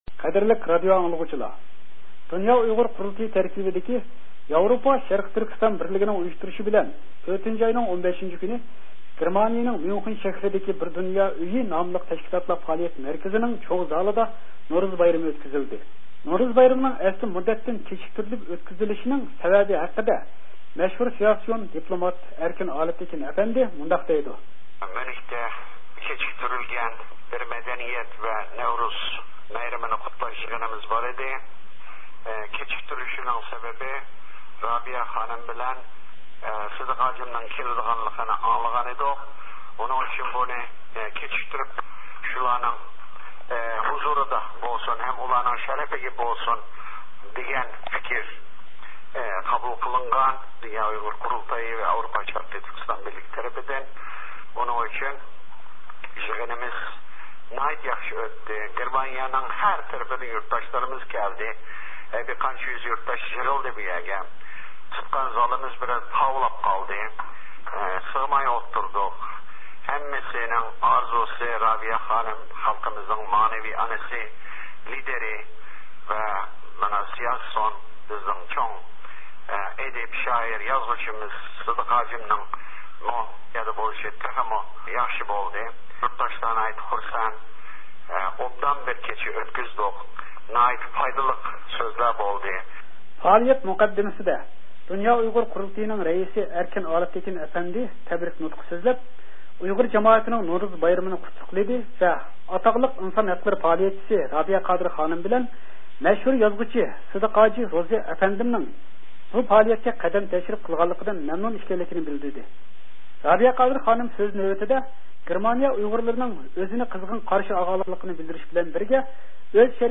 بۇ مۇناسىۋەت بىلەن گېرمانىيىدىكى ئىختىيارى مۇخبىرىمىز دۇنيا ئۇيغۇر قۇرۇلتىيىنىڭ رەئىسى ئەركىن ئالىپتېكىن ئەپەندىنى زىيارەت قىلىپ، ئۇنىڭ بۇ ھەقتىكى قاراشلىرىنى سورىدى.